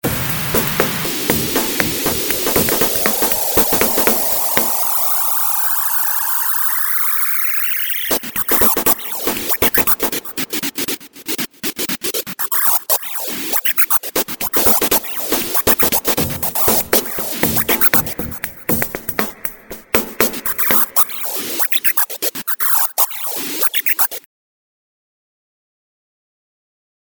He created a bunch of loops that you can use in your own compositions if you want.